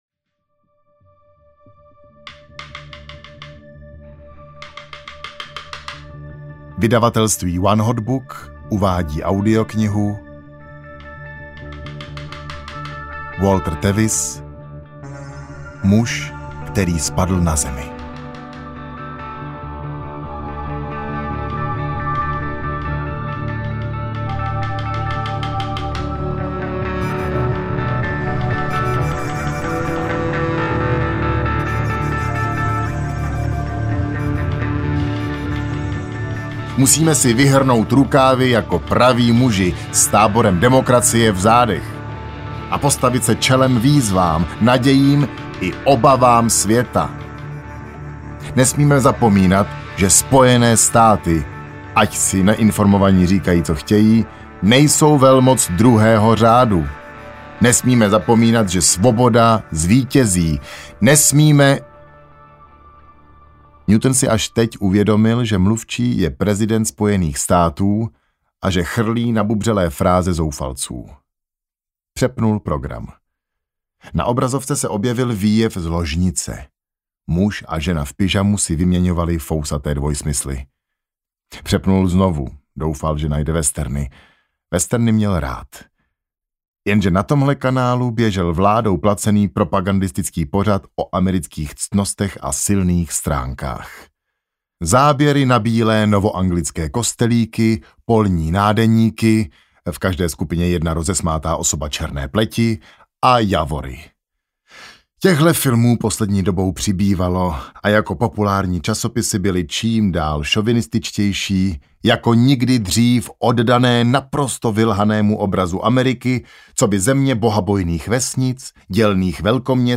Muž, který spadl na Zemi audiokniha
Ukázka z knihy
• InterpretIgor Orozovič